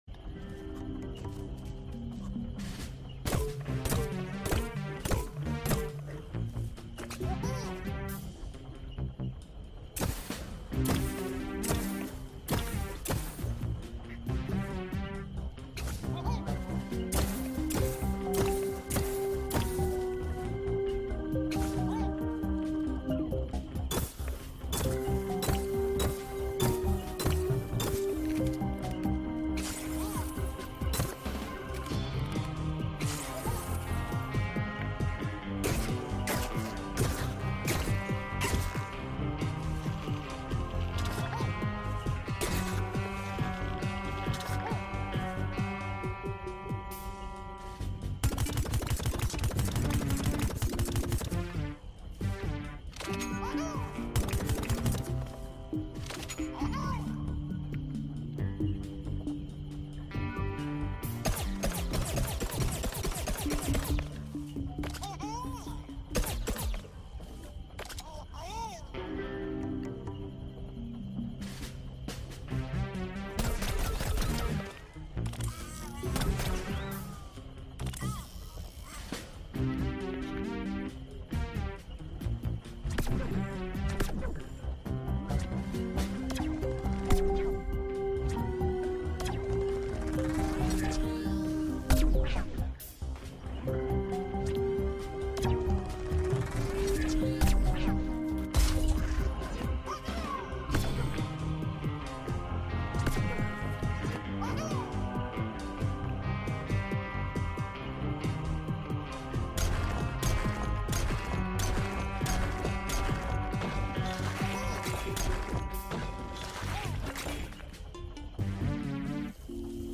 Plants vs. Zombies Garden Warfare sound effects free download
Plants vs. Zombies Garden Warfare 2 all peashooter shooting sounds